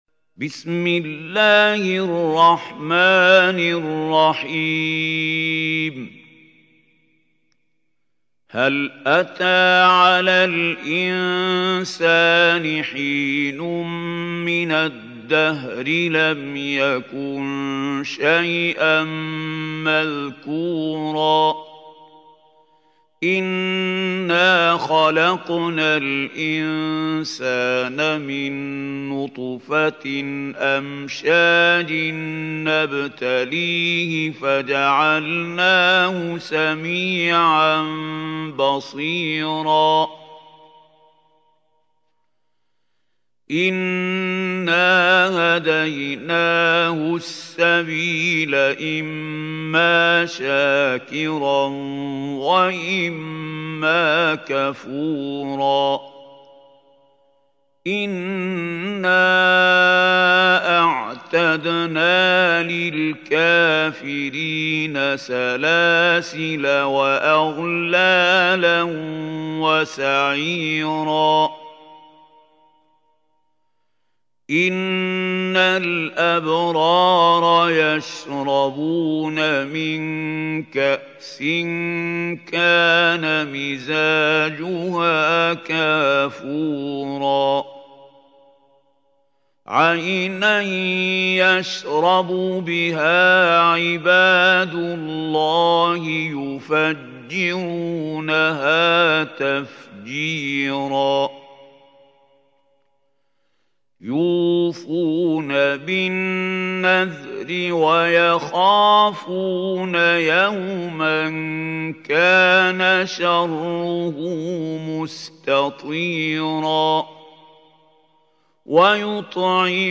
سورة الإنسان | القارئ محمود خليل الحصري